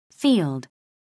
나는 학창시절은 되돌아 본다. ⓔ field 미국 [fiːld] 듣기 -들판, 목초지, 논, 밭, 농장, 광활하게 펼쳐진 곳, 땅 -지역, 싸움터, 현장 -분야, 범위, 영역 I 'm interested in the field of education.